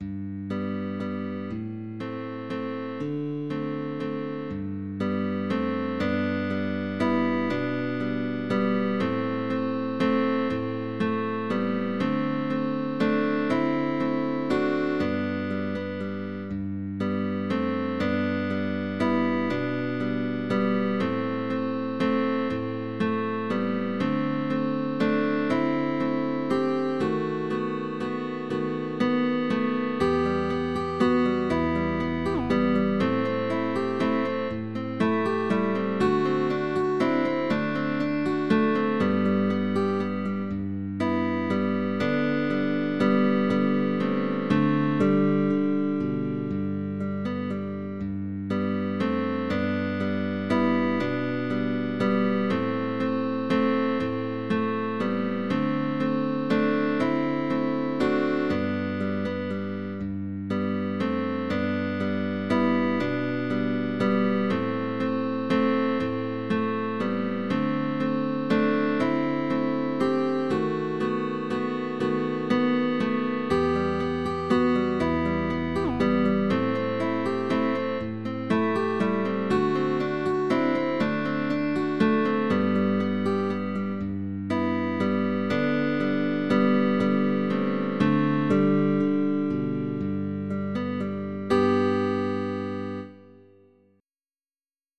MELODIC GUITAR.